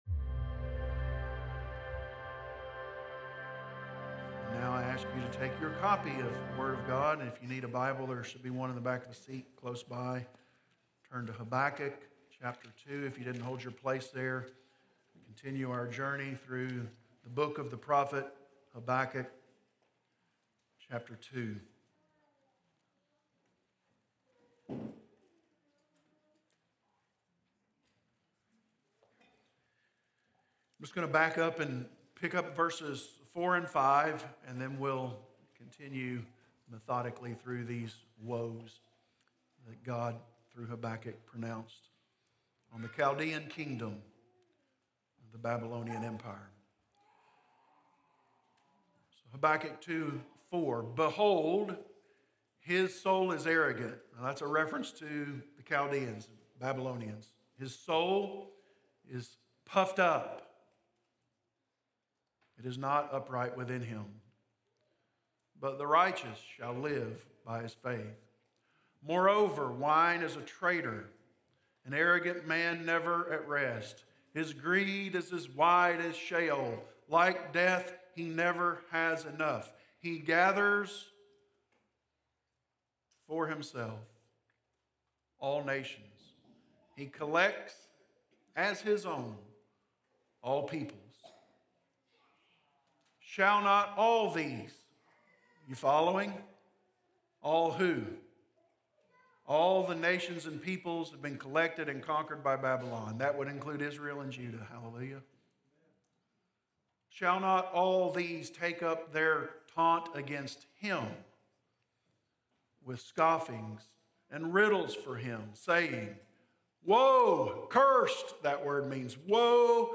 Explore other Sermon Series
Sermons recorded during the Sunday morning service at Corydon Baptist Church in Corydon, Indiana